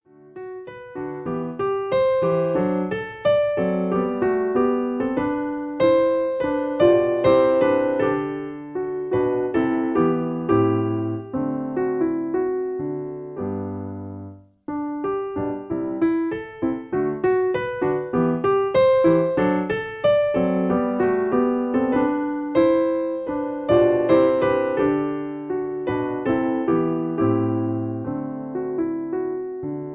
Piano accompaniment. Original key